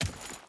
Footsteps
Dirt Chain Run 4.wav